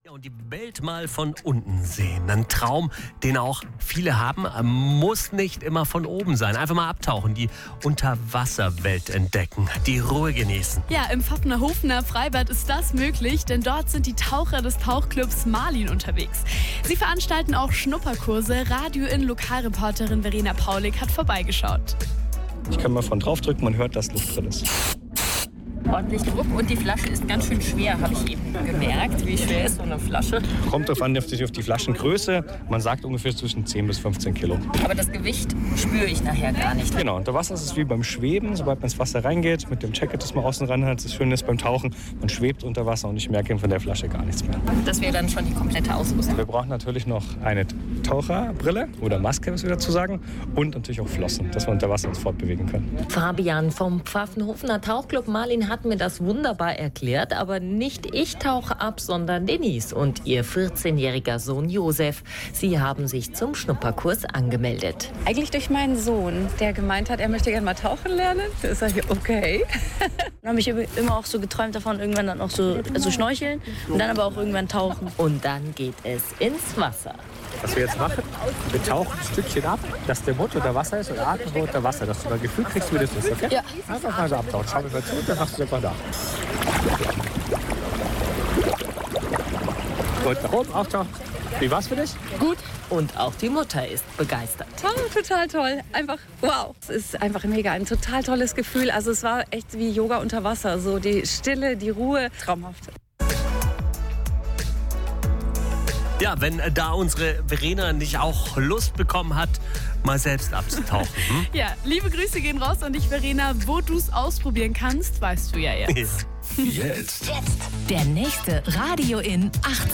Radio IN hat uns besucht und zwei spannende Interviews aufgenommen.